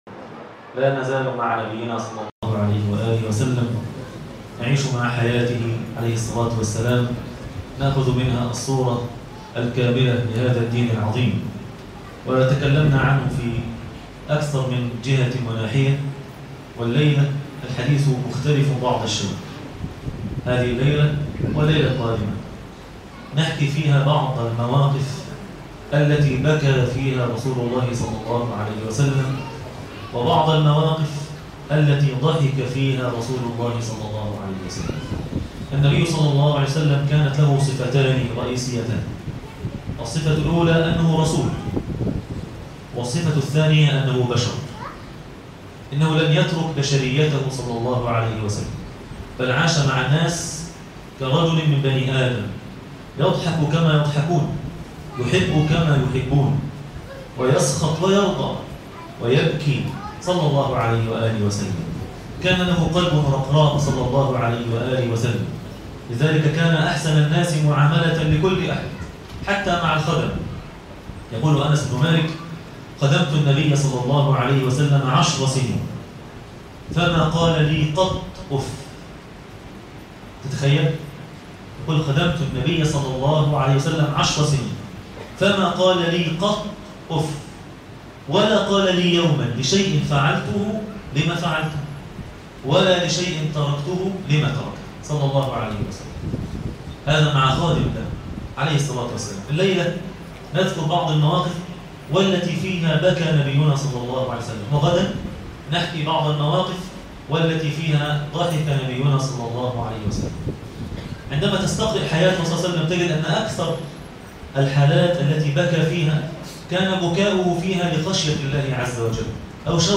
وهنا بكي النبي ( صلي الله عليه وسلم ) - درس التراويح